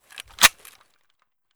mp153_close_empty.ogg